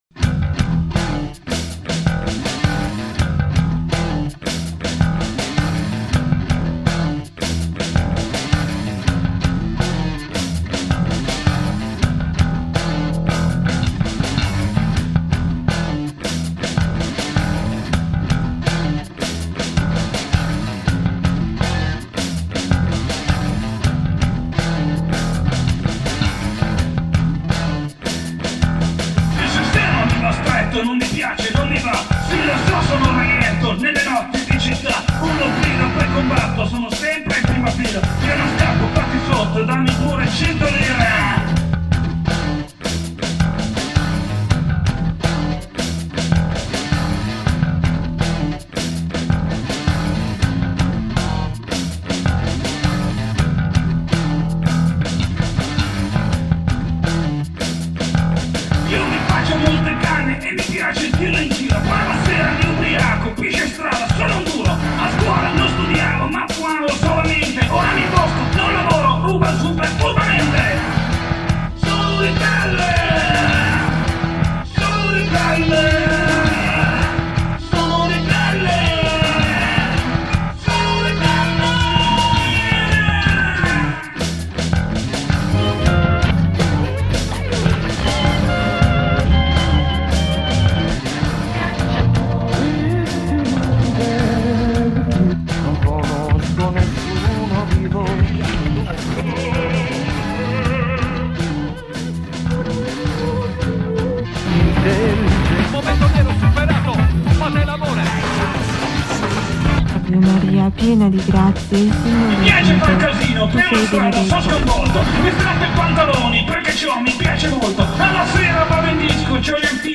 vocal
bass
guitars